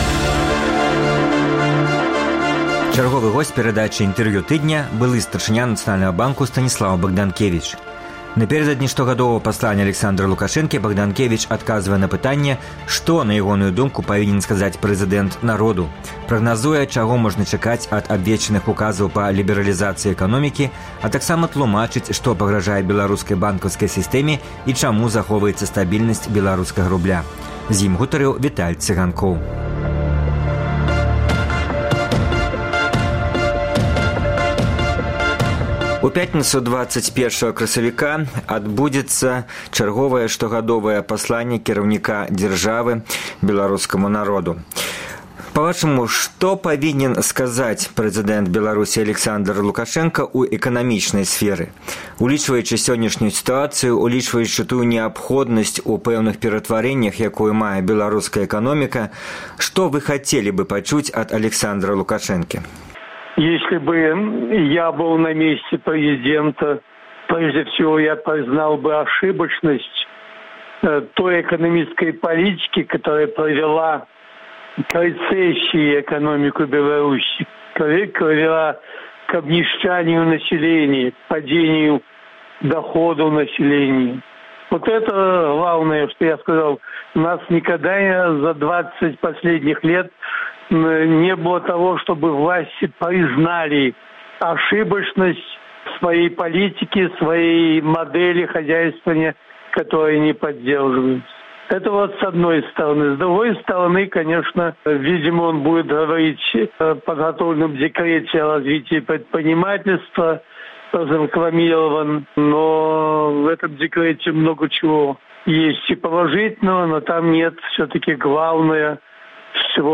Госьць перадачы “Інтэрвію тыдня” – былы Старшыня Нацыянальнага Банку Станіслаў Багданкевіч. Напярэдадні штогадовага пасланьня Аляксандра Лукашэнкі Багданкевіч адказвае на пытаньне, што, на ягоную думку, павінен сказаць прэзыдэнт народу, прагназуе, чаго можна чакаць ад абвешчаных указаў па лібералізацыі эканомікі, а таксама тлумачыць, што пагражае беларускай банкаўскай сыстэме і чаму захоўваецца стабільнасьць беларускага рубля.